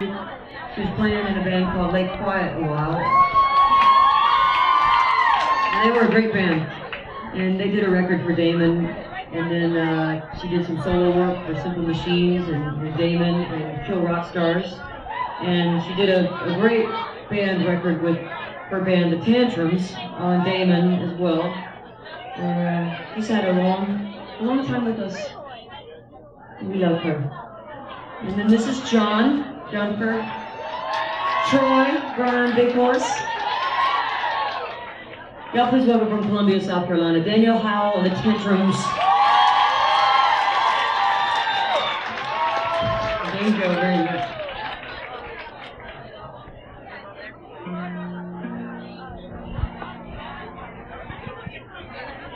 cat's cradle - chapel hill, north carolina